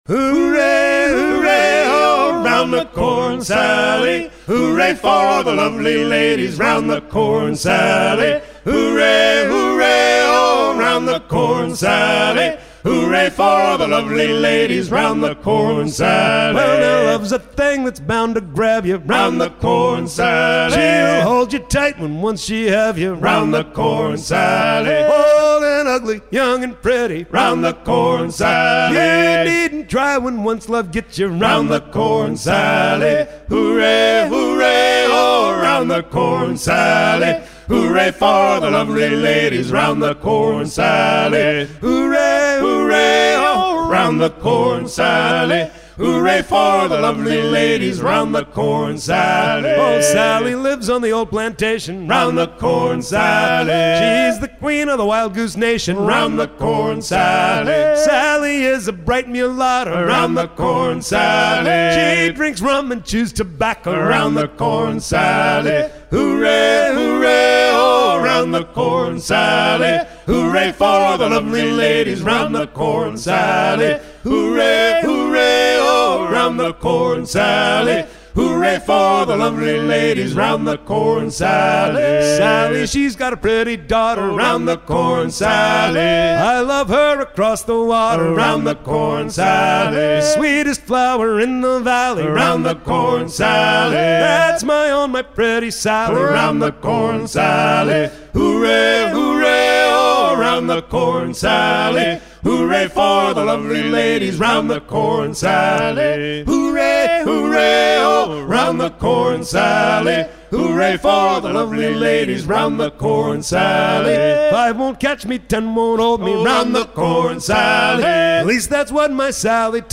à hisser main sur main
maritimes